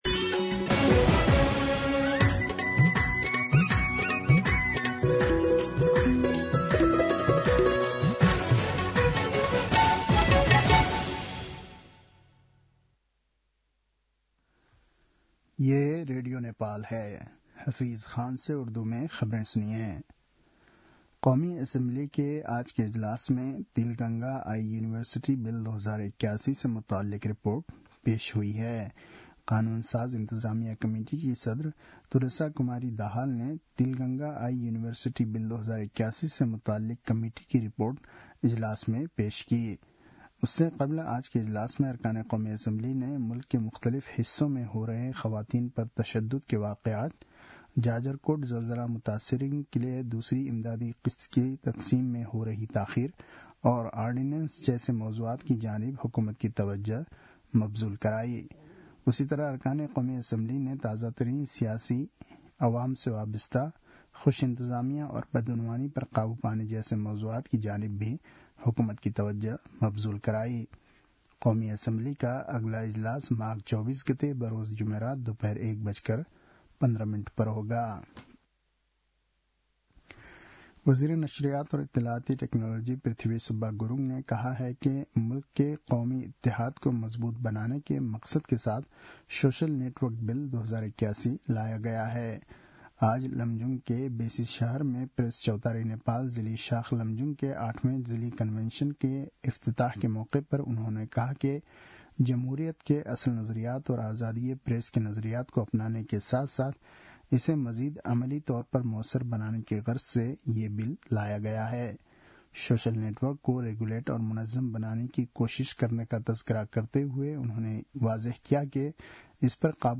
उर्दु भाषामा समाचार : २१ माघ , २०८१
Urdu-News-10-20.mp3